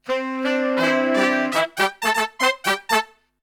FUNK2 AM.wav